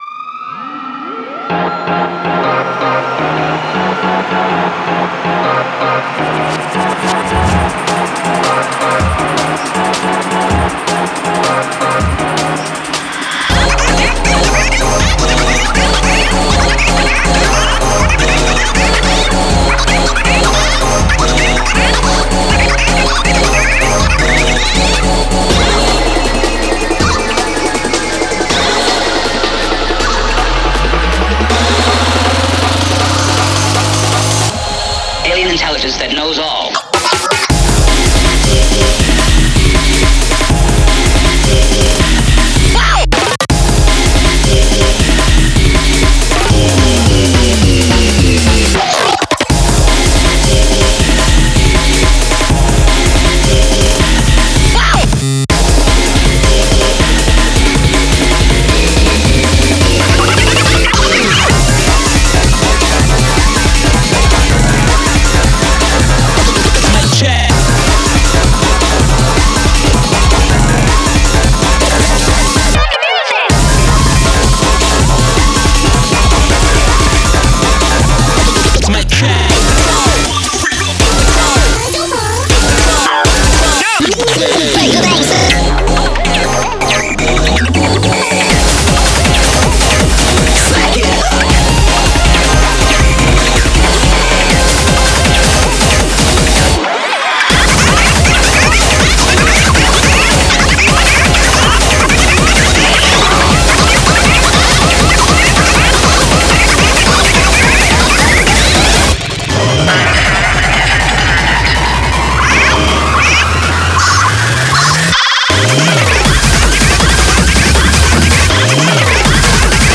BPM80-160
Audio QualityMusic Cut